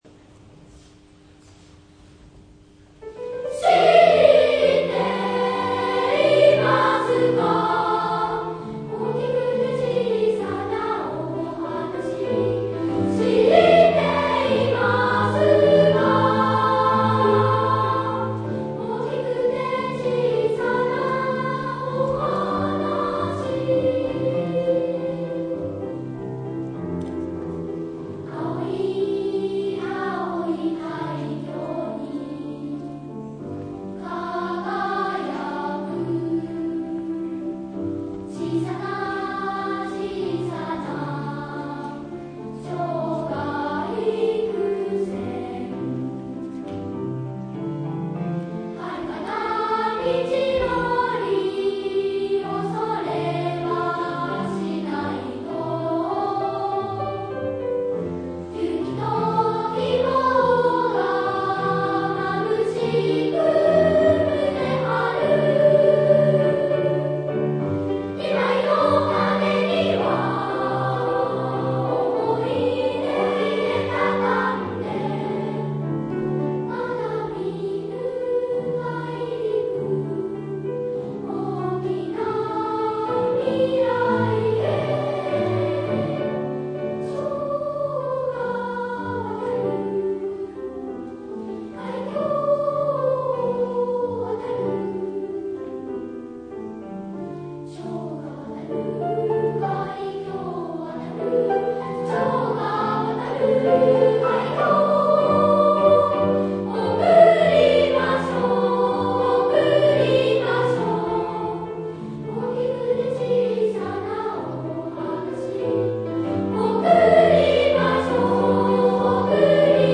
暗唱発表会
合唱部発表